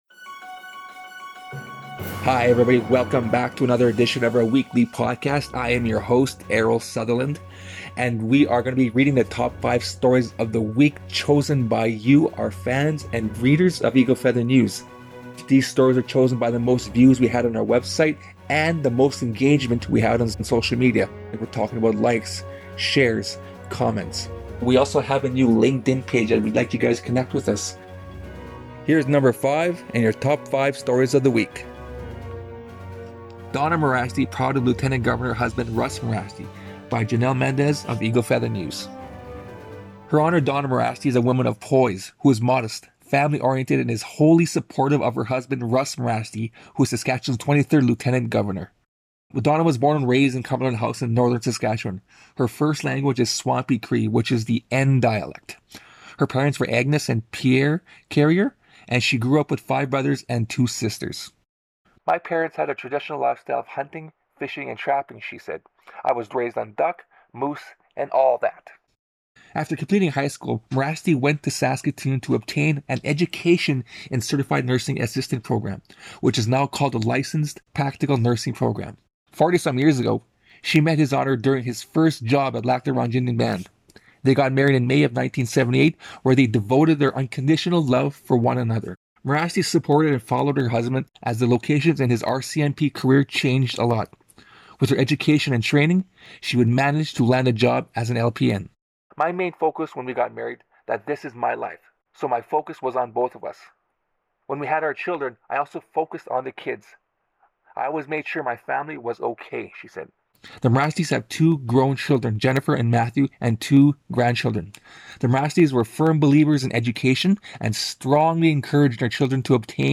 Welcome to Eagle Feather News weekly podcast show that provides the audio for our most-read, online stories of each week.